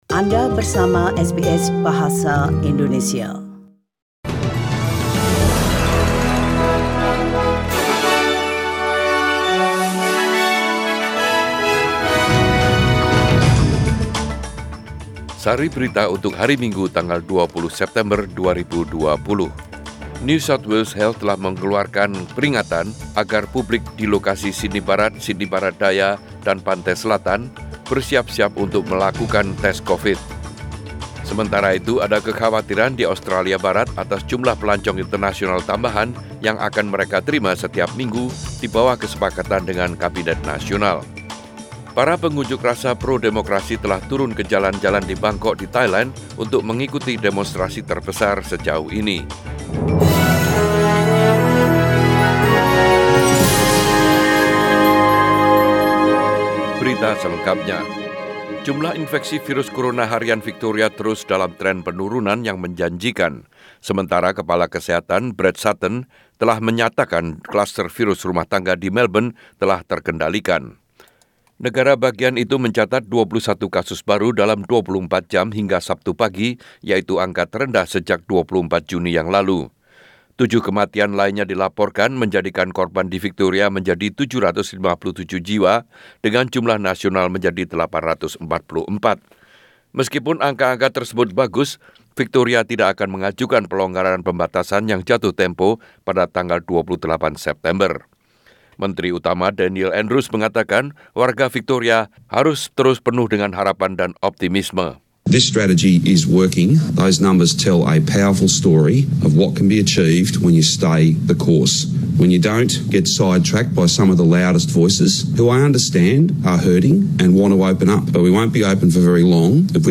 SBS Radio News in Bahasa Indonesia - 20 Sep 2020